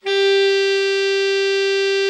Index of /90_sSampleCDs/Giga Samples Collection/Sax/ALTO SAX